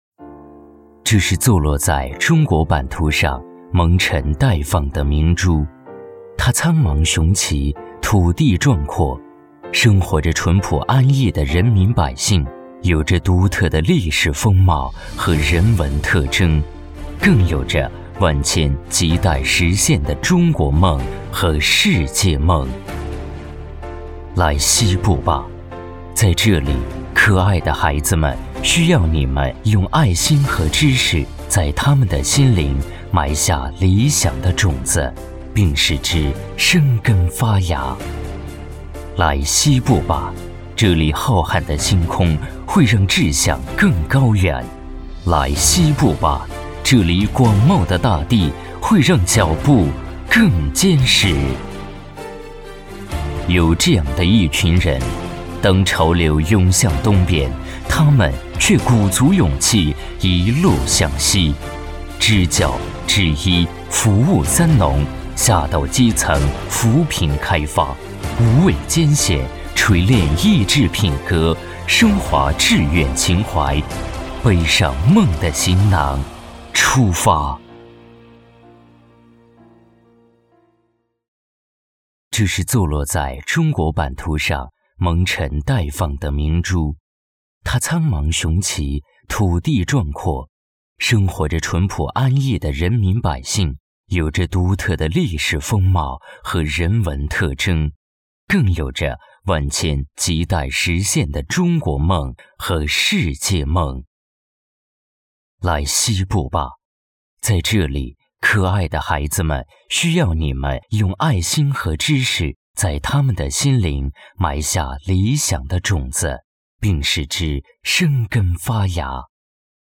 政府专题配音